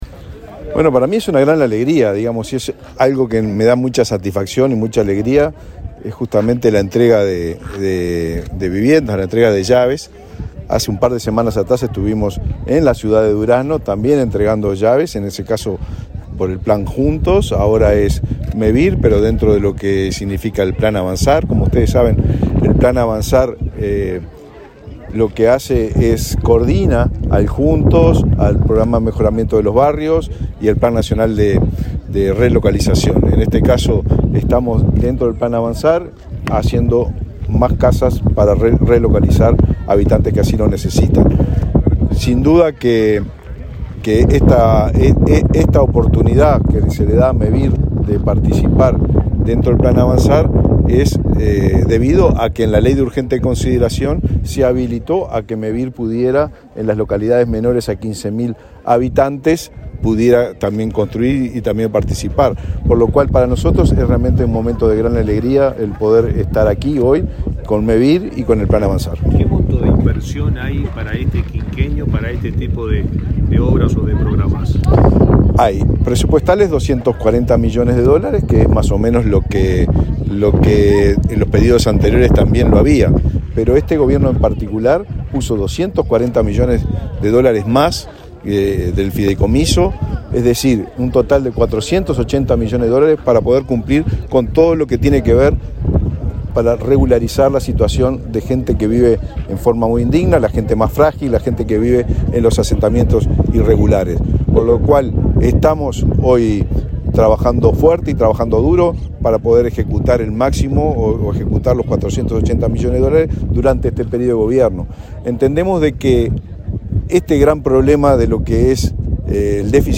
Declaraciones del ministro de Vivienda, Raúl Lozano
El ministro de Vivienda, Raúl Lozano, dialogó con la prensa en Sarandí del Yí, departamento de Durazno, antes de participar en la inauguración de